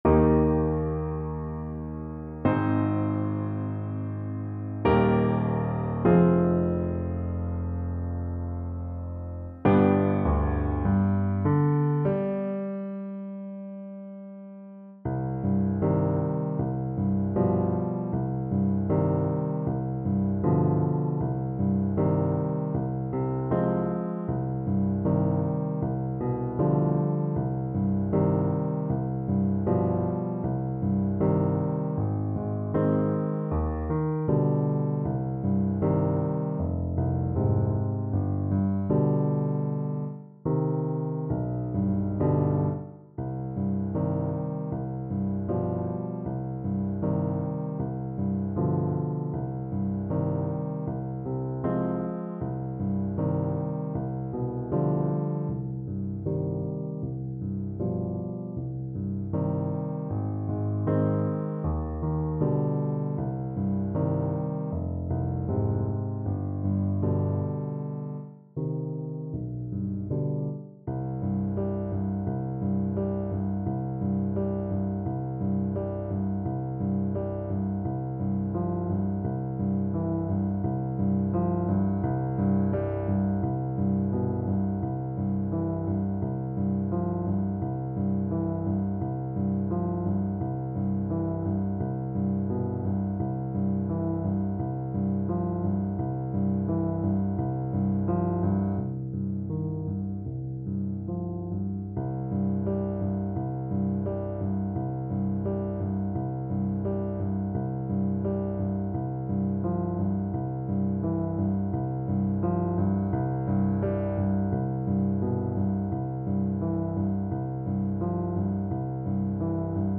Largo
Classical (View more Classical Trumpet Music)